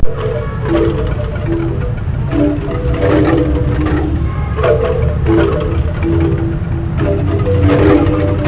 Water wheel musical instrument at
the Reunification Palace, Ho Chi Minh City, Vietnam